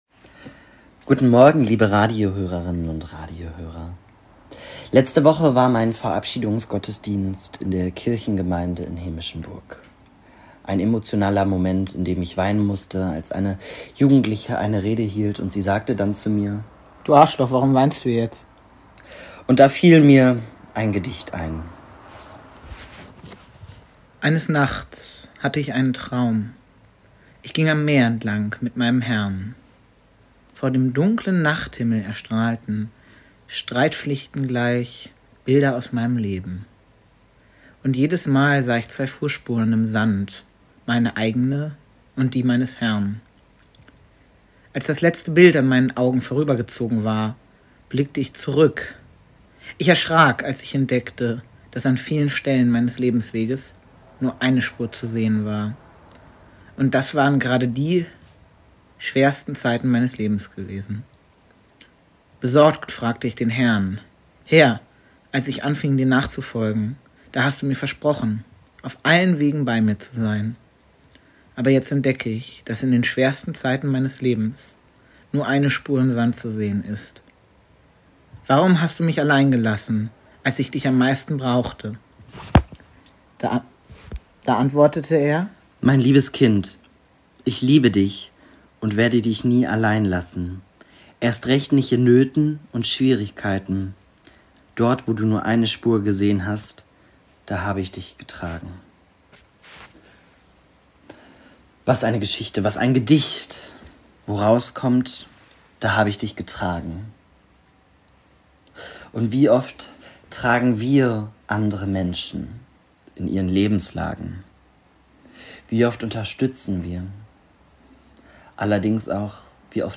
Radioandacht vom 24. Juli